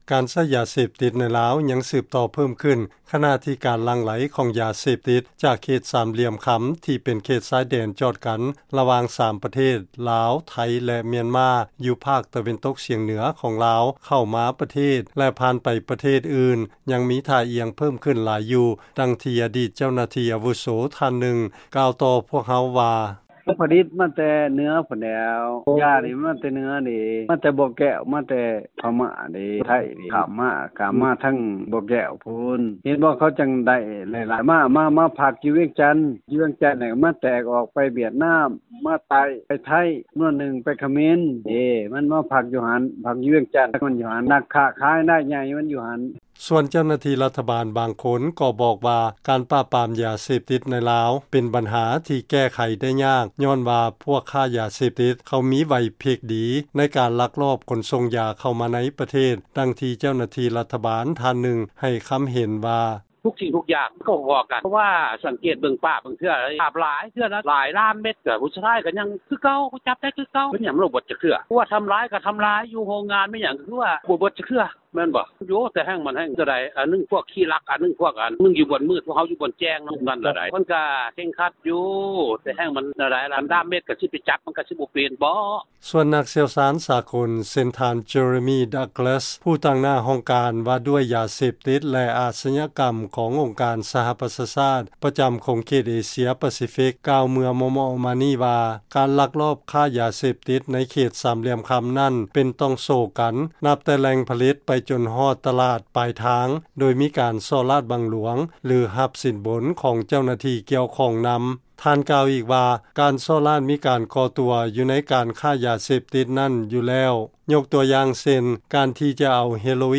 ຟັງລາຍງານ ການຕິດຢາເສບຕິດ ສືບຕໍ່ສູງຂຶ້ນນັບມື້ ຂະນະທີ່ ການຄ້າຢາເສບຕິດ ໃນຂົງເຂດລຸ່ມ ແມ່ນໍ້າຂອງເພີ້ມສູງຂຶ້ນ